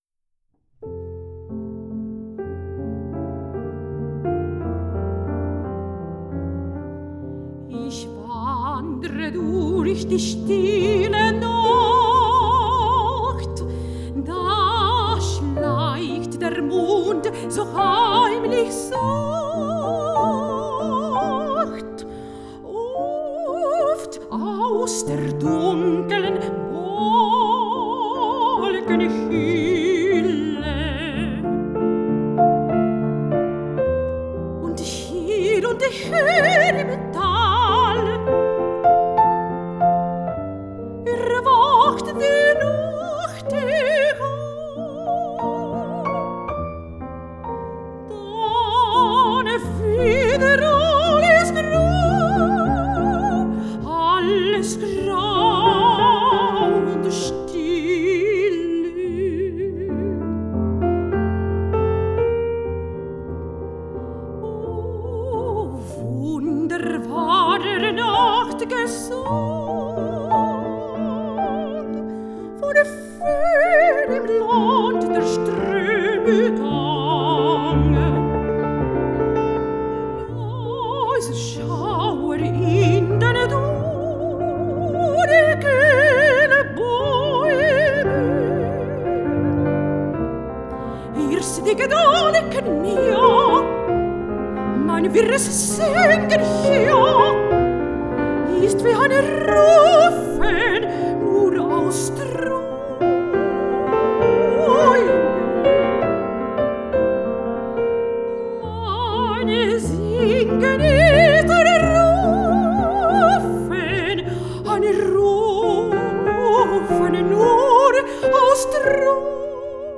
Mujeres y género: Clara Wiek y Fanny Mendelssohn, compilación de obras escritas para voz y piano
El objetivo fundamental de esta grabación es la difusión de obras de compositoras activas durante el periodo romántico de Alemania, desde una perspectiva de la interpretación históricamente informada.
Fanny Mendelssohn, Romanticismo Alemán, Género Lied